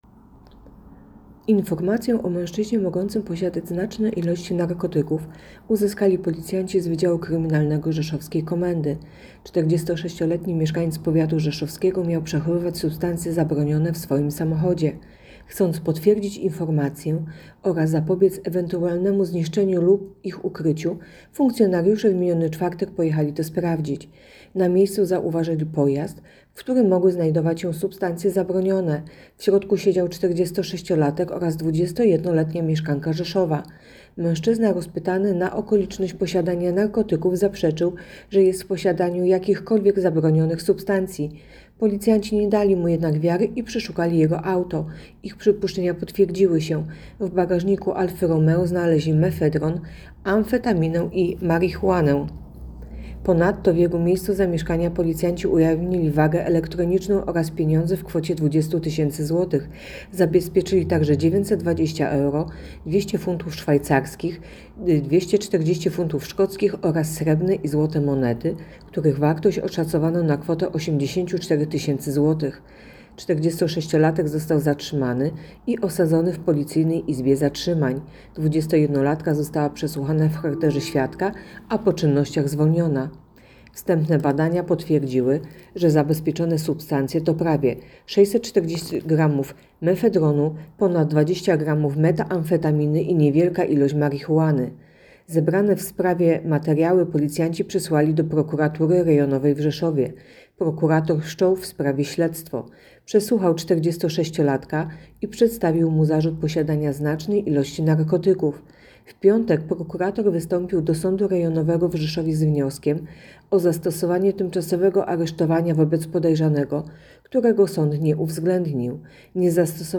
Nagranie audio Mówi podkomisarz